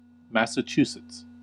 Massachusetts (/ˌmæsəˈsɪts/
En-us-Massachusetts.ogg.mp3